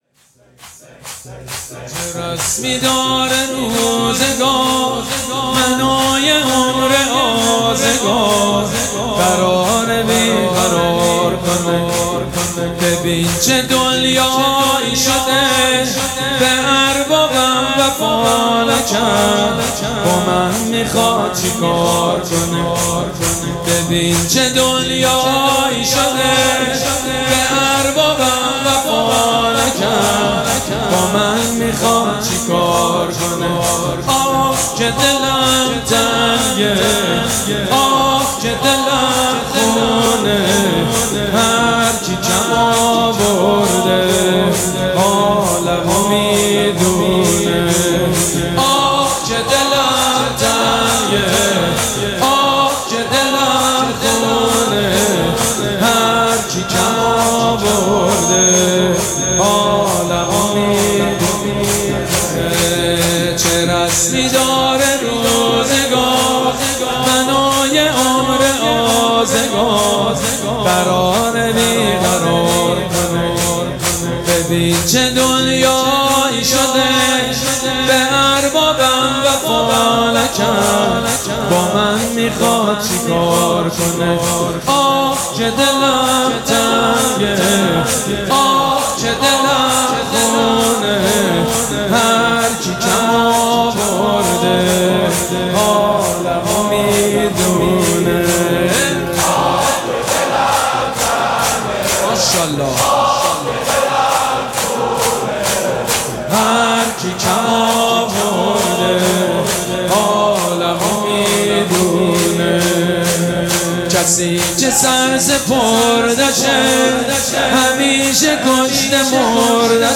مراسم عزاداری شب دهم محرم الحرام ۱۴۴۷
شور
حاج سید مجید بنی فاطمه